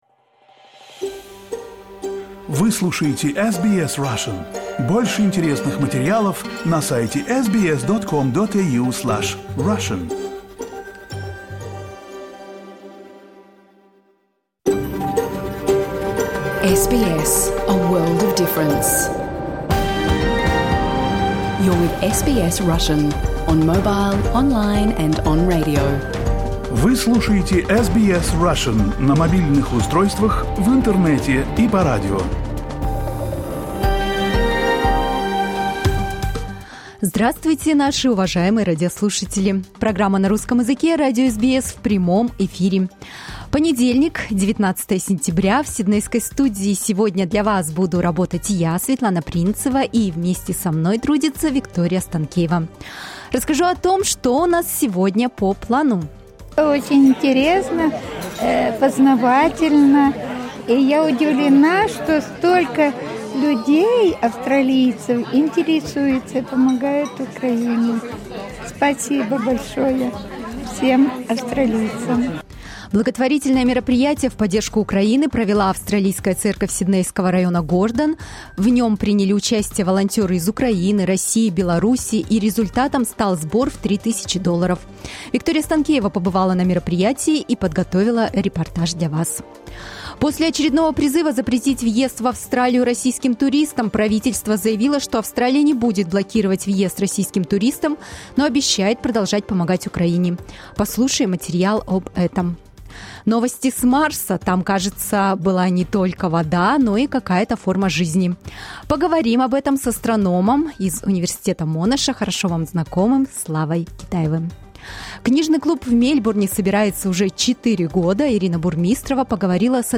You can listen to SBS Russian program live on the radio, on our website and on the SBS Radio app.